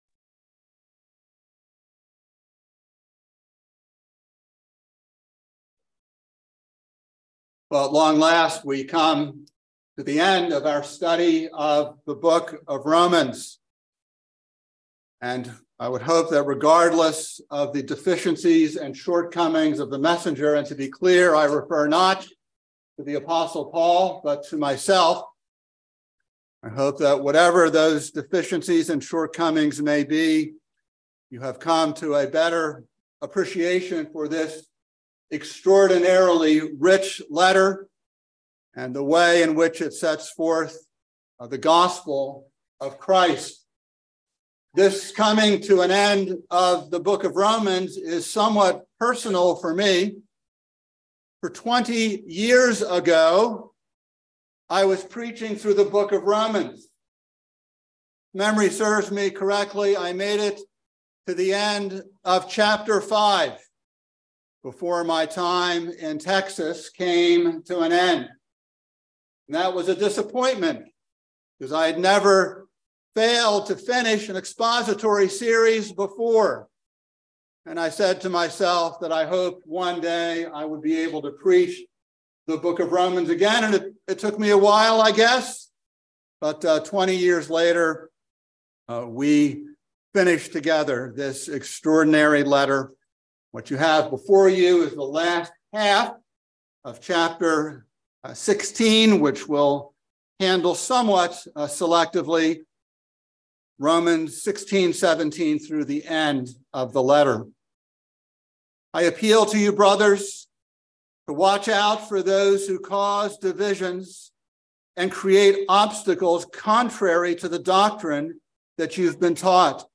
by Trinity Presbyterian Church | May 24, 2023 | Sermon